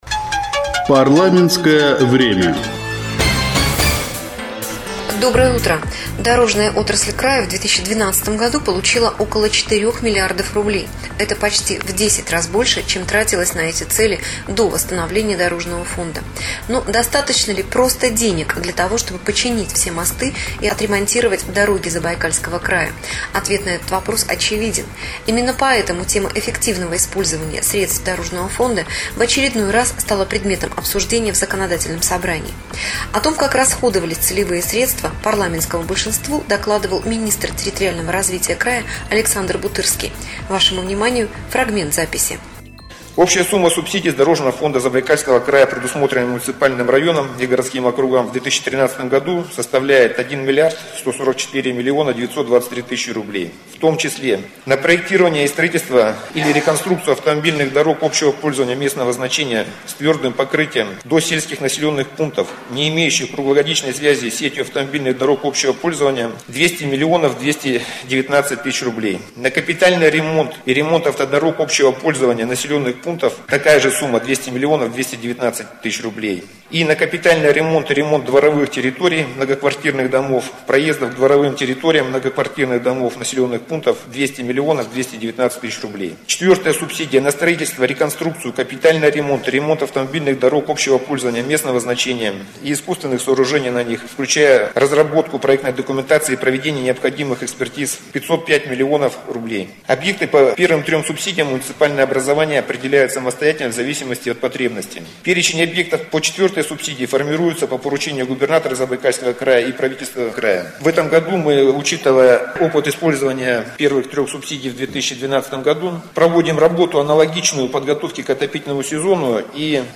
Информационный сюжет "Дорожный фонд. Новые рельсы"
Информационный сюжет подготовлен по материалам заседания фракции "Единая Россия" (26 февраля 2013 г.)